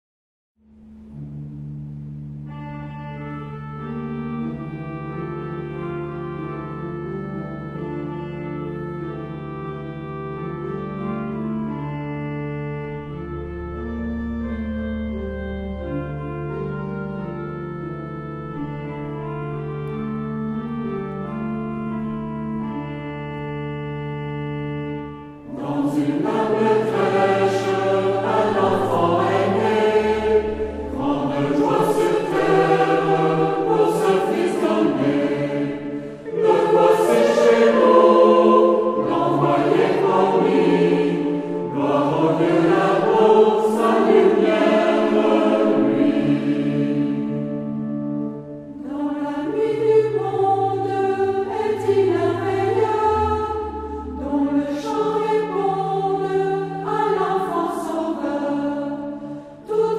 Genre-Style-Form: Canticle ; Sacred
Mood of the piece: collected
Type of Choir: FH  (2 mixed voices )
Tonality: D minor